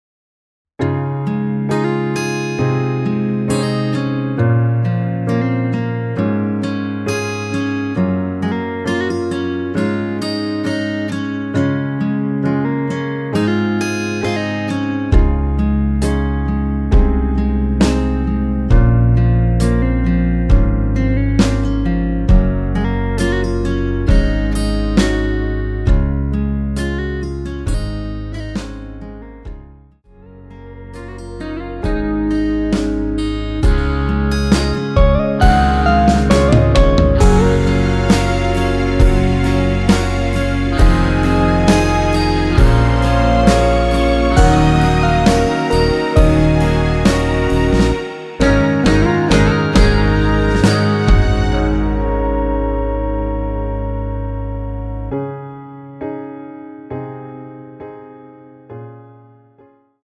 원키에서 +5 올린 1절후 후렴(2절삭제)으로 진행되는 MR 입니다.(미리듣기및 가사 참조)
Db
앞부분30초, 뒷부분30초씩 편집해서 올려 드리고 있습니다.
중간에 음이 끈어지고 다시 나오는 이유는